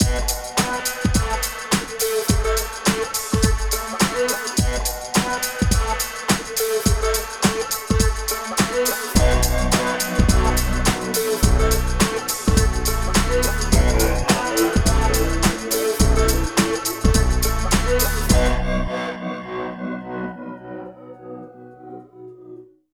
18 LOOP   -R.wav